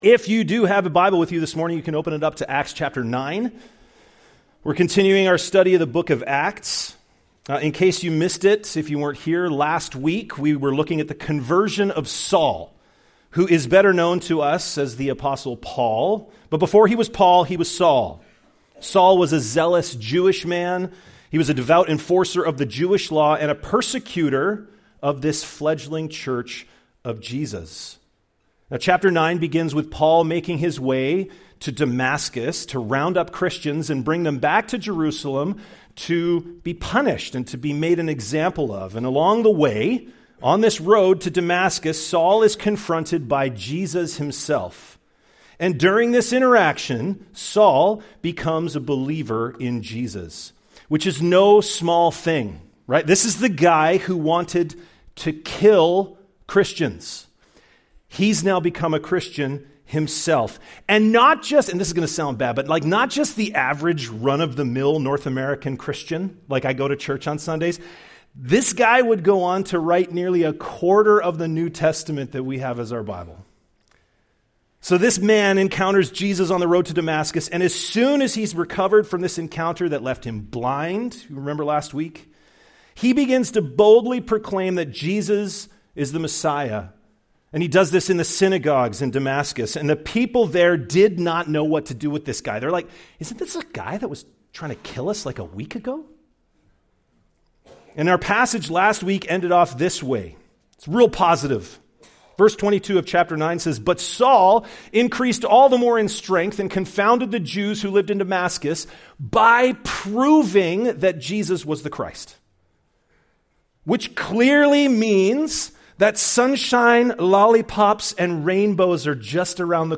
Part of our series, ACTS: Mission & Message (click for more sermons in this series).